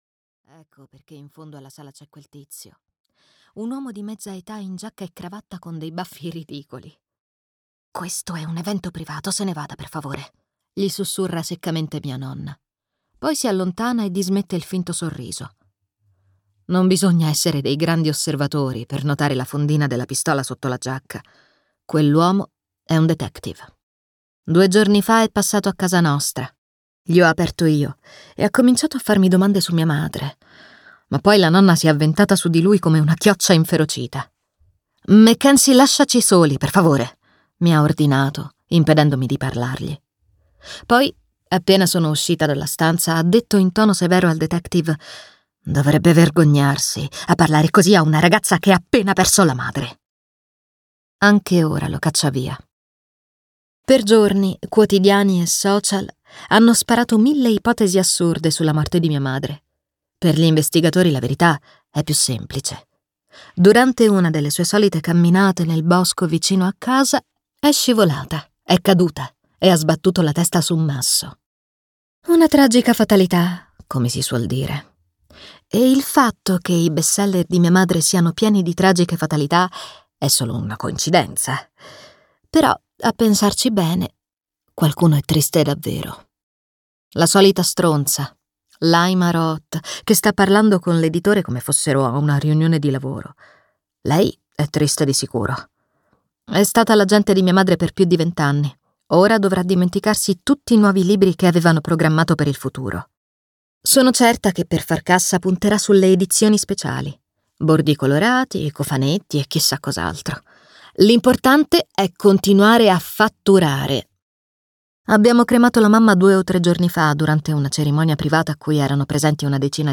"Love, Mom" di Iliana Xander - Audiolibro digitale - AUDIOLIBRI LIQUIDI - Il Libraio